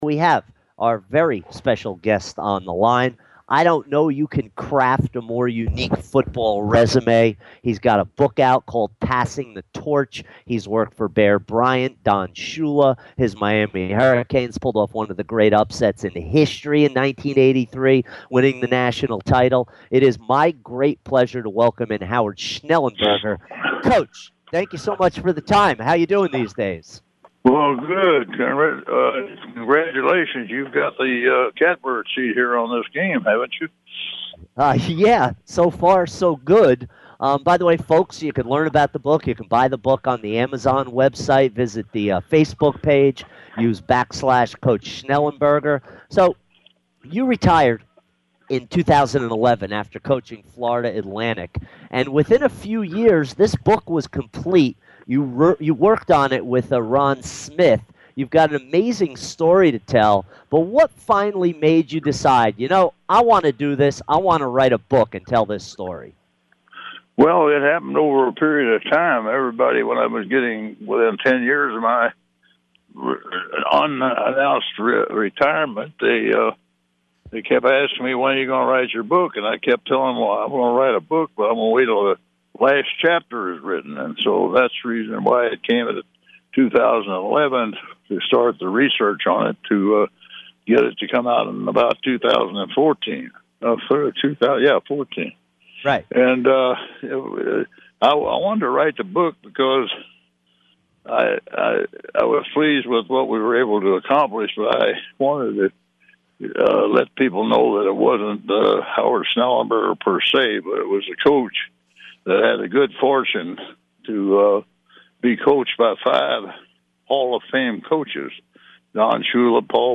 Throwback Interview - Howard Schnellenberger 1-11-16